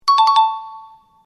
Tải âm thanh "Correct Answer GameShow" - Hiệu ứng âm thanh chỉnh sửa video